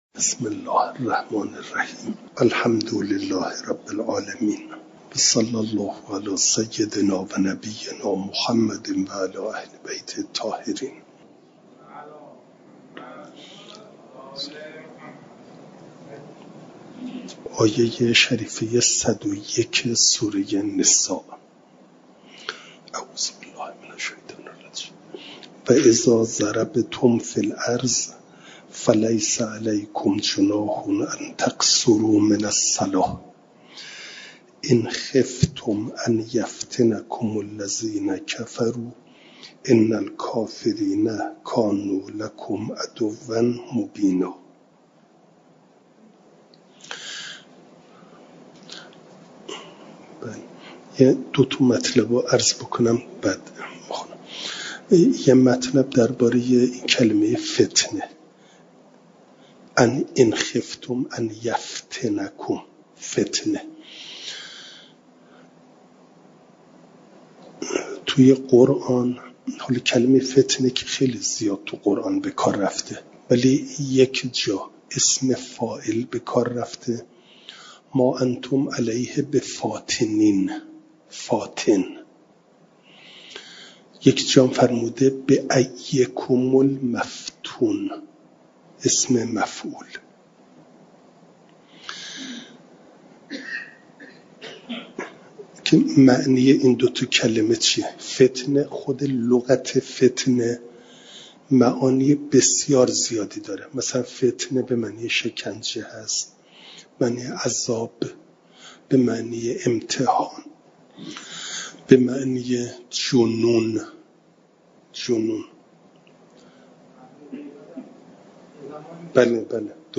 جلسه سیصد و هشتاد و ششم درس تفسیر مجمع البیان